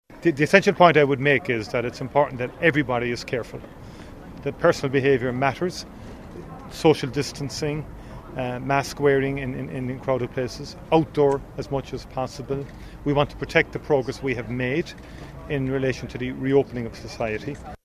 Taoiseach Micheál Martin is urging people to be cautious as the Delta variant continues to spread in Ireland: